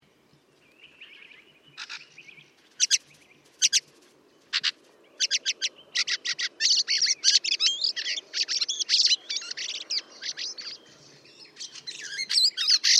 Här nedan hittar du en lista med fågelsång och fågelläten av mer än 400 fågelarter.
Björktrast Fieldfare Turdus pilaris
Bjorktrast.mp3